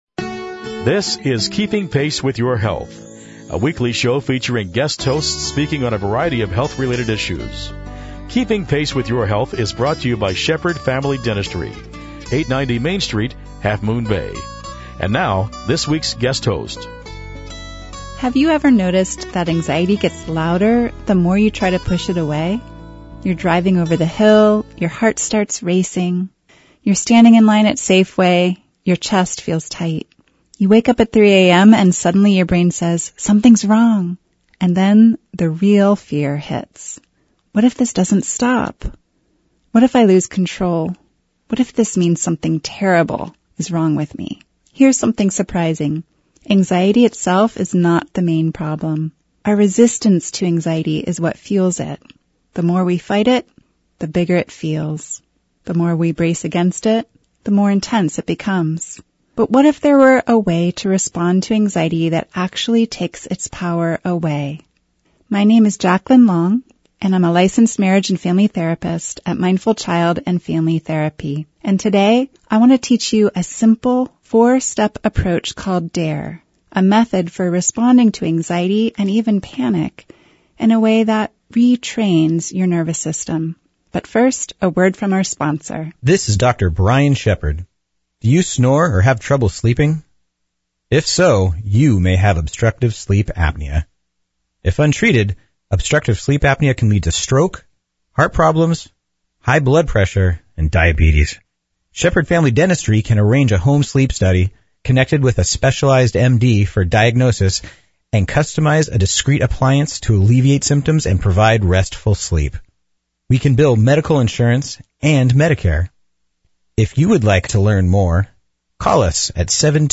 Keeping Pace with Your Health is a show devoted to keeping you healthy! Each week we present a different guest host speaking on a wide range of health related topics.